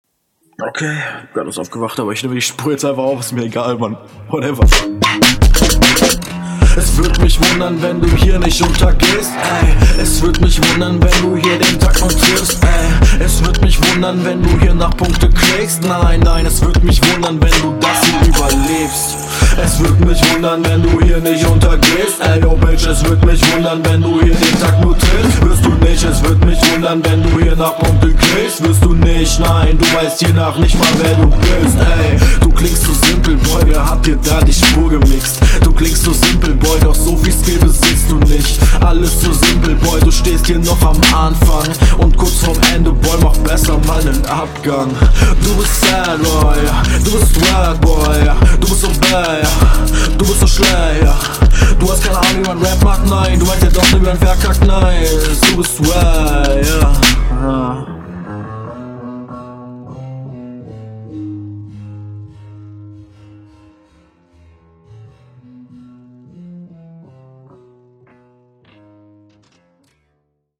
Der Beat ist sau voll gepackt.